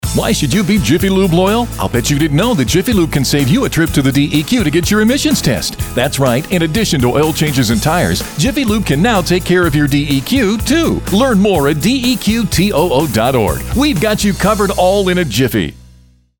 15 second ad